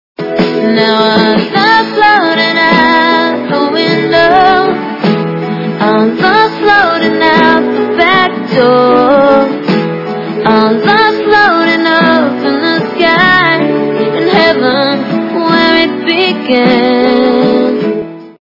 качество понижено и присутствуют гудки